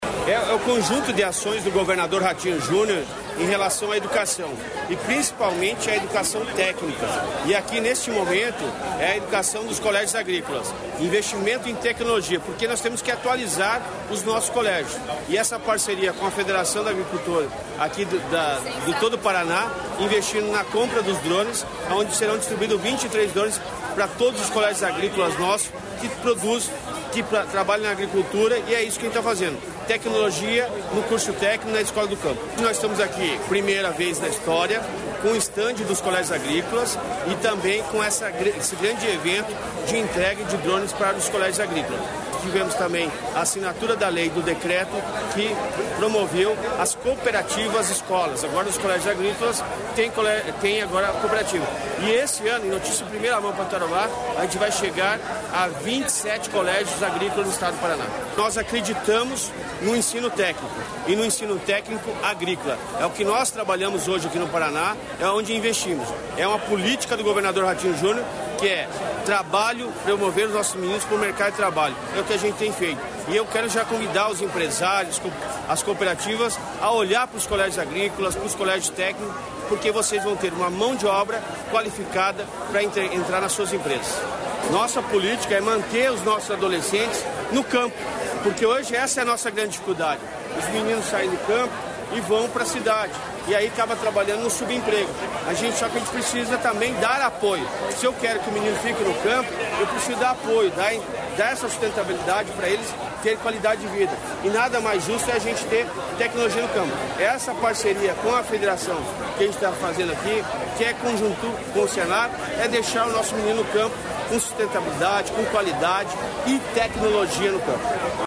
Sonora do secretário da Educação, Roni Miranda, sobre a entrega de drones